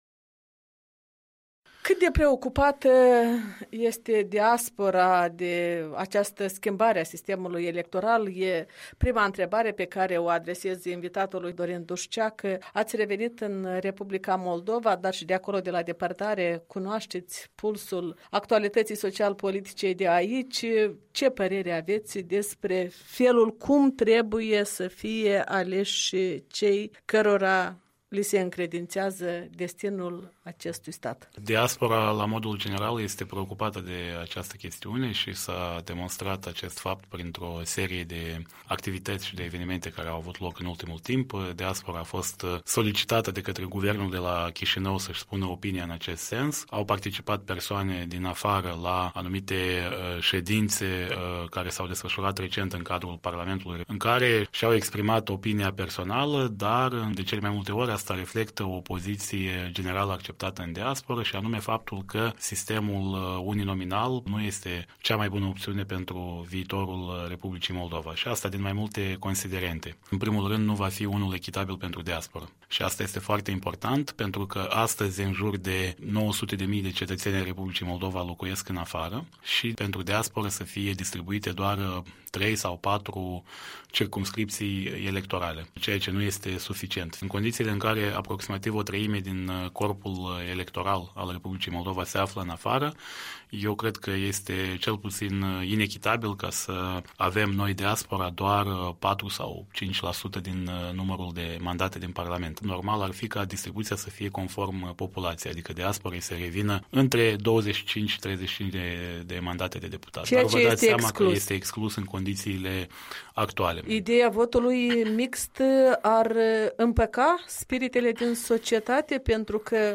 Despre proiectul de modificare a sistemului electoral, în dialog cu unul din activiștii diasporei moldovene.
Interviu